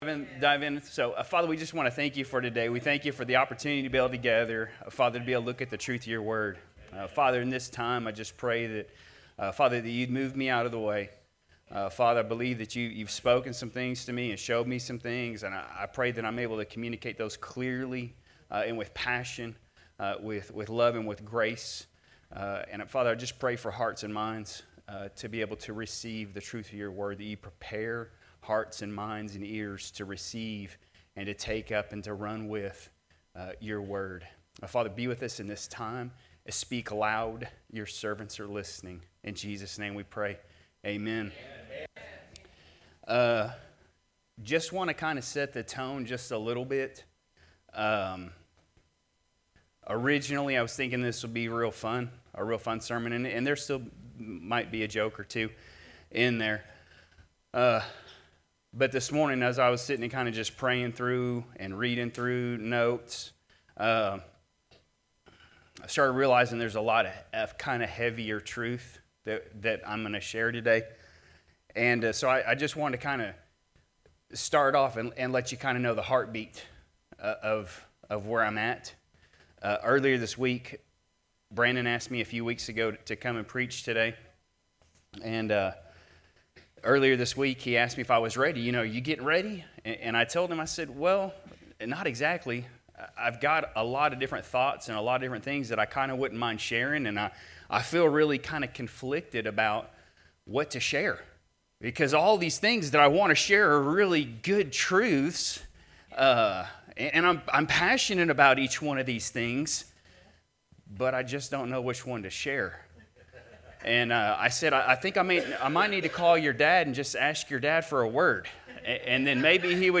Sermons | New Life Ministries